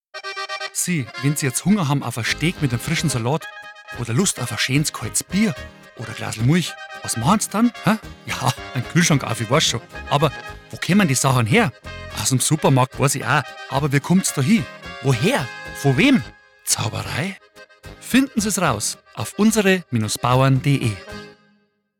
UBB_Radiospot_Fleischi.mp3